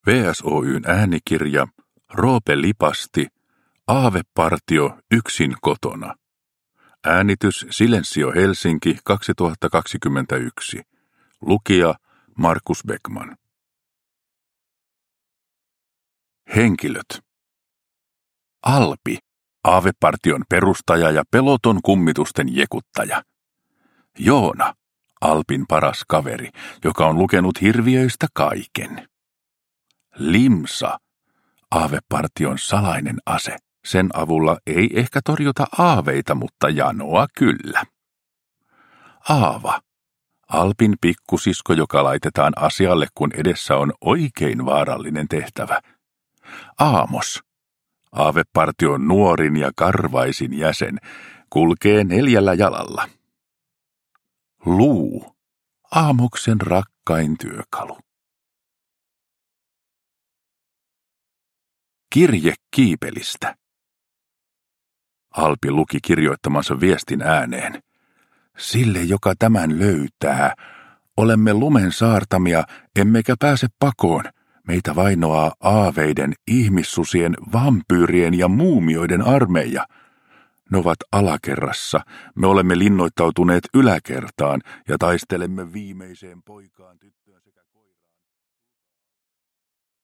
Aavepartio yksin kotona – Ljudbok